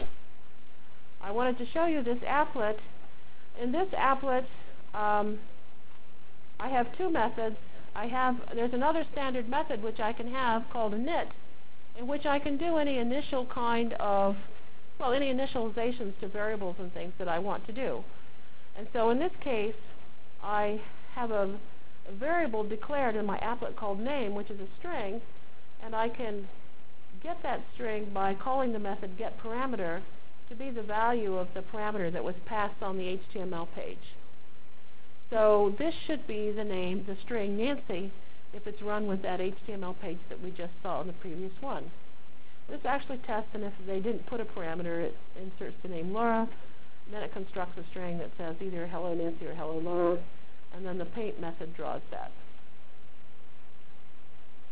From Jan 22 Delivered Lecture for Course CPS616 -- Java Lecture 1 -- Overview CPS616 spring 1997 -- Jan 22 1997.